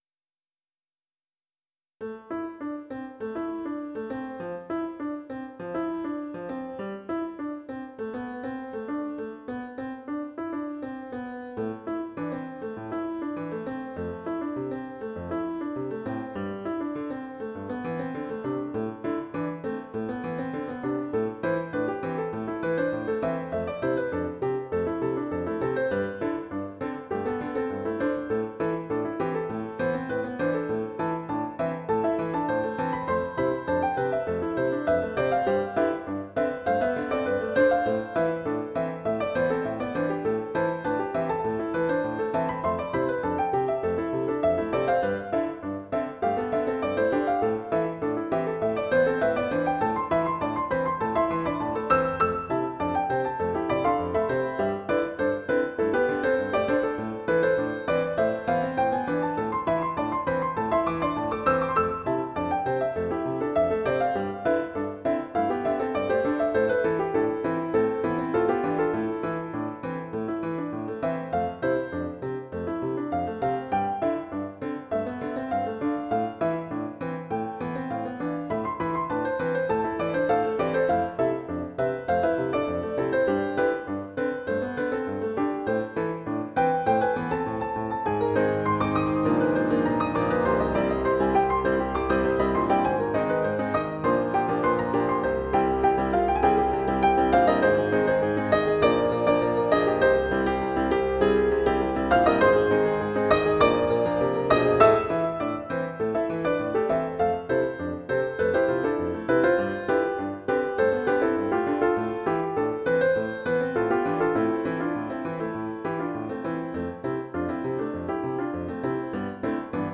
Title TGIF Opus # 376 Year 2006 Duration 00:02:29 Self-Rating 5 Description I consider this more of a guide than anything strict. mp3 download wav download Files: mp3 wav Tags: Duet, Piano Plays: 1707 Likes: 0